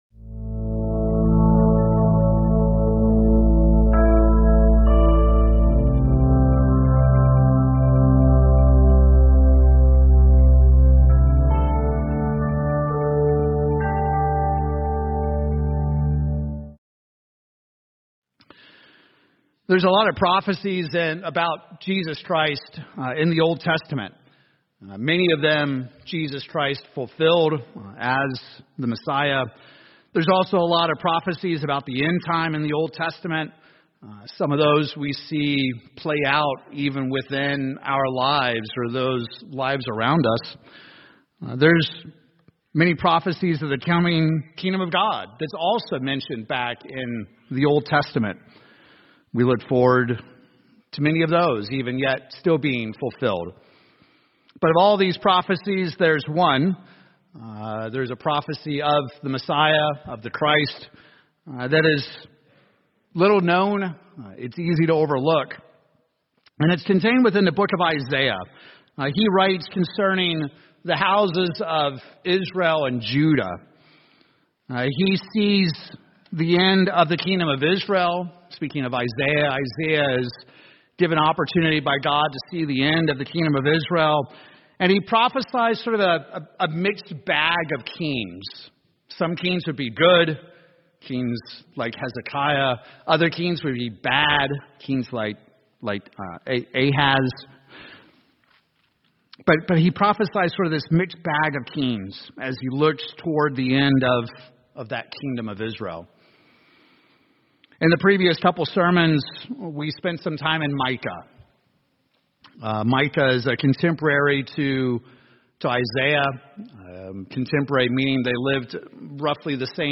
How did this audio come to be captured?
Given in Tulsa, OK Oklahoma City, OK